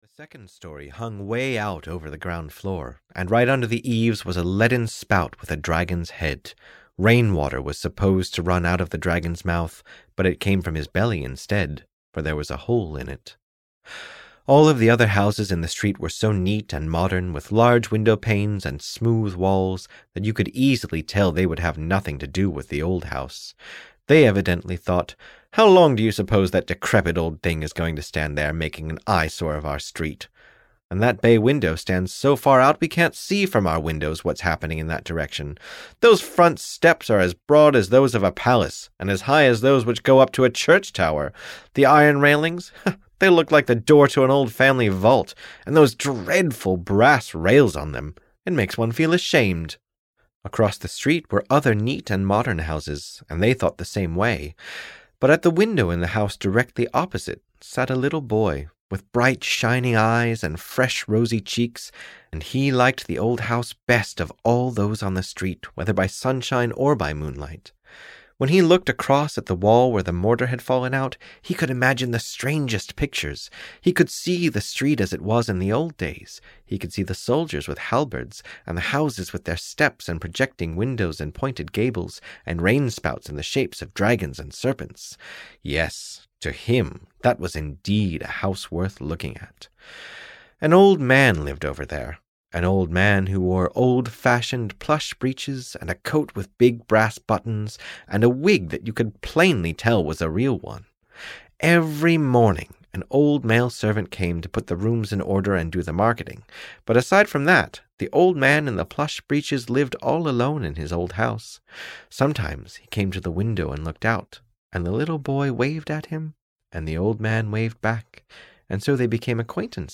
The Old House (EN) audiokniha
Ukázka z knihy